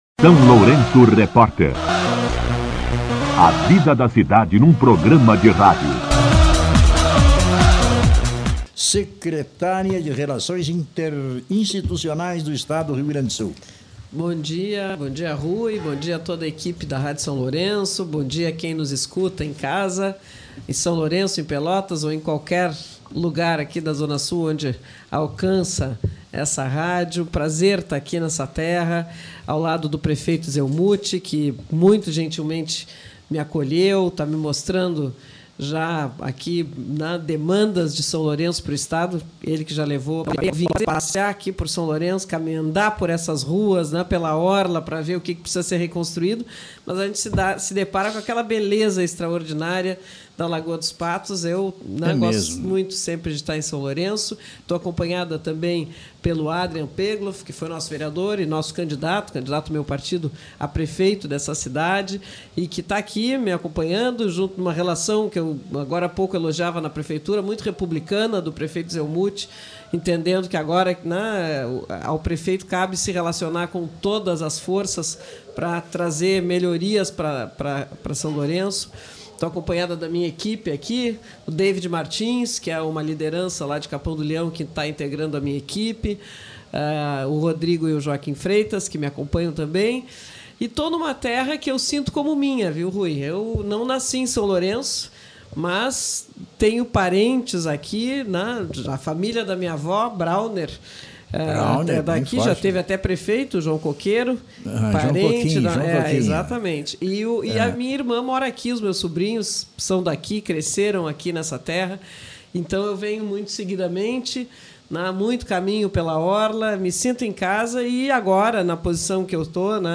Entrevista com a secretária extraordinária de Relações Institucionais do Estado, Paula Mascarenhas e o prefeito Zelmute Marten
A secretária extraordinária de Relações Institucionais do Estado, Paula Mascarenhas, acompanhada do prefeito Zelmute Marten, esteve no SLR RÁDIO nesta sexta-feira (14) para abordar as demandas de contenção climática. O chefe do Executivo solicita auxílio do Estado para a construção dessas medidas.